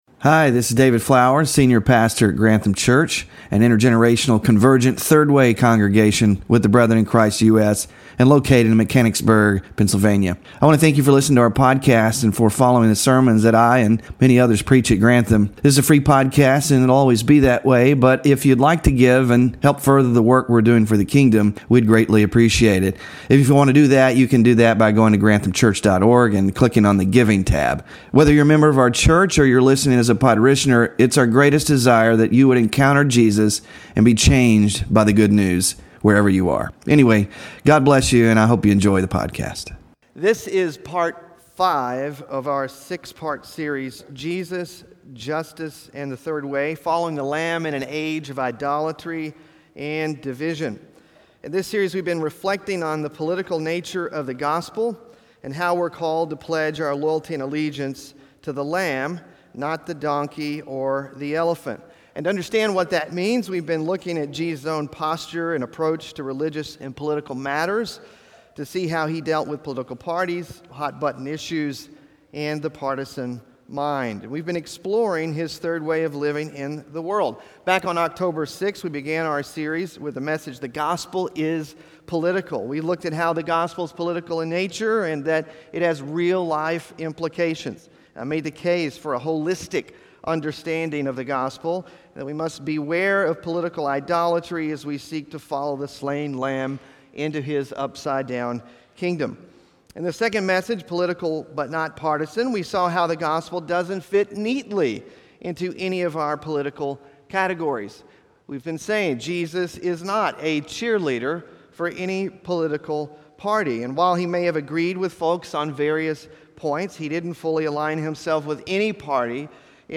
OUR THIRD WAY WITNESS SERMON SLIDES (5th OF 6 IN SERIES) SMALL GROUP DISCUSSION QUESTIONS (11-3-24) BULLETIN (11-3-24)